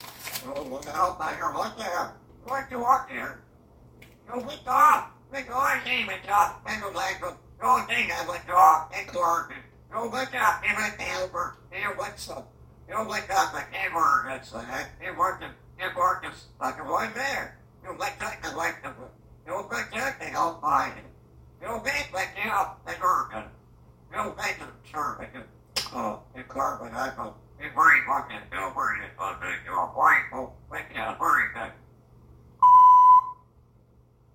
Congo African Grey is really in love with me.
He is curious, active, and talks funny.